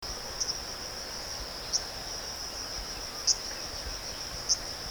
Calls
7 May 2012 Tin Shui Wai (1066)